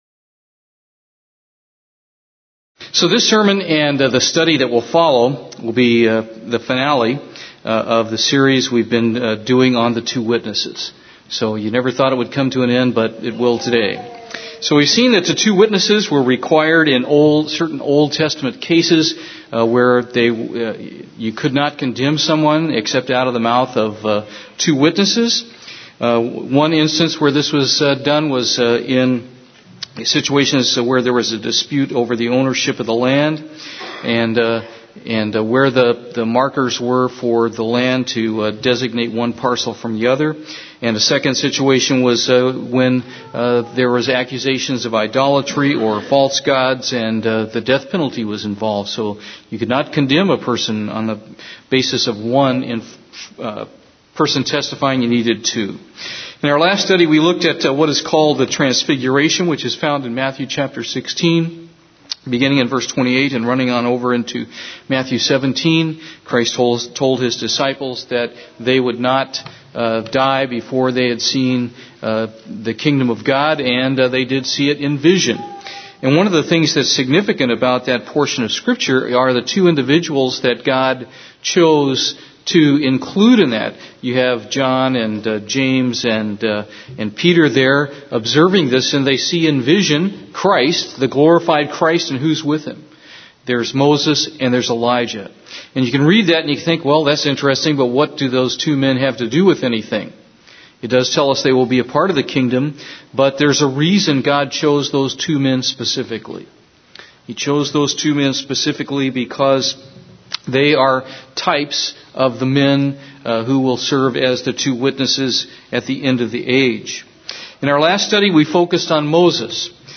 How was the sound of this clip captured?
Given in Houston, TX